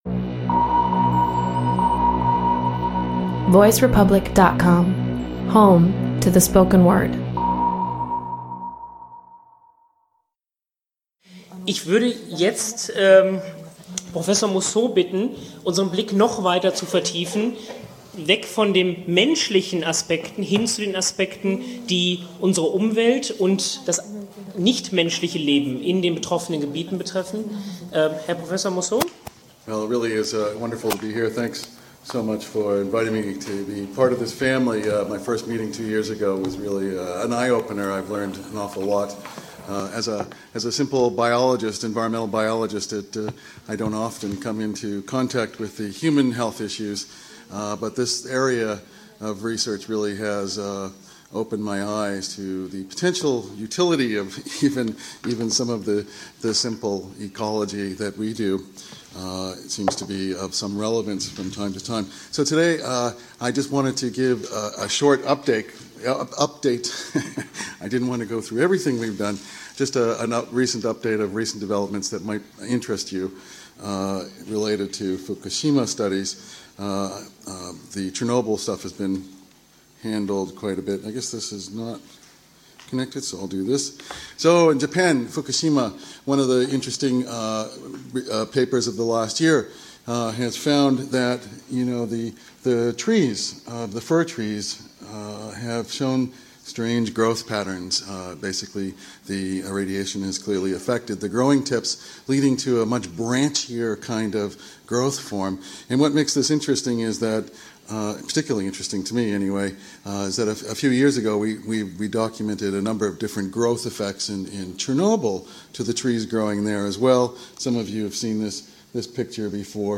slide presentation